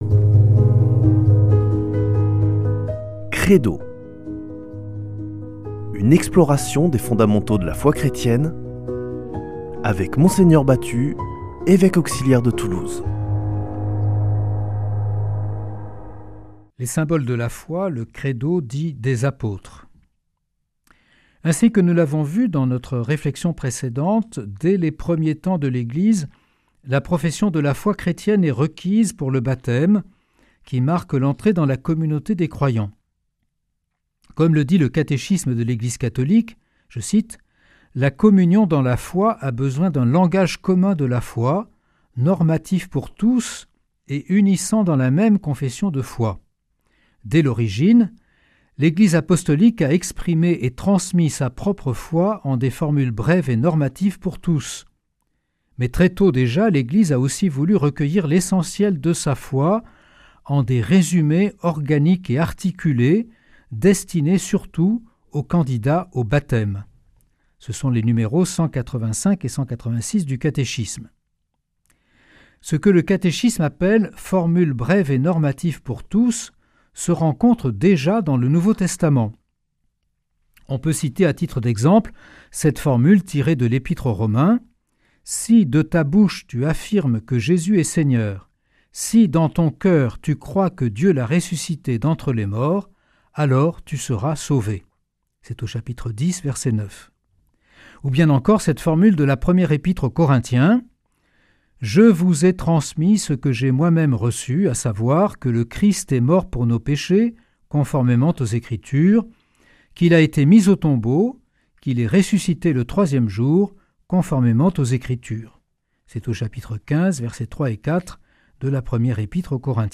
Partager Copier ce code (Ctrl+C) pour l'intégrer dans votre page : Commander sur CD Une émission présentée par Mgr Jean-Pierre Batut Evêque auxiliaire de Toulouse Voir la grille des programmes Nous contacter Réagir à cette émission Cliquez ici Qui êtes-vous ?